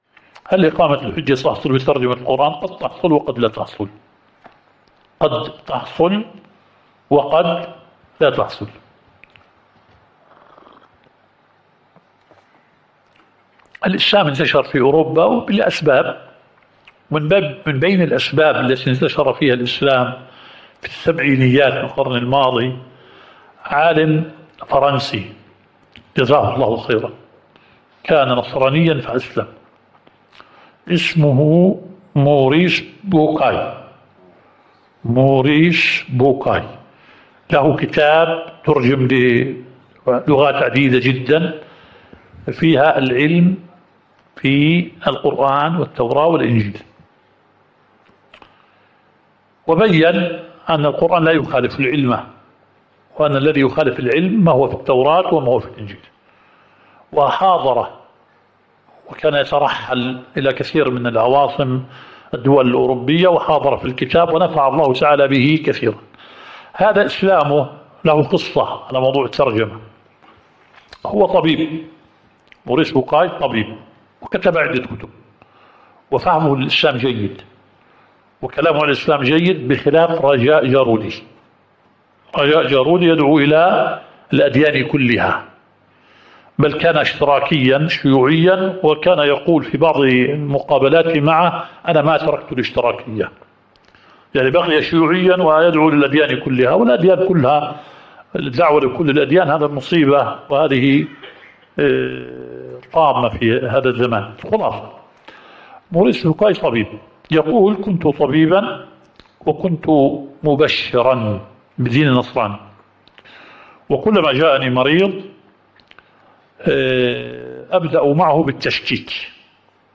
الدورة الشرعية الثالثة للدعاة في اندونيسيا – منهج السلف في التعامل مع الفتن – المحاضرة الرابعة.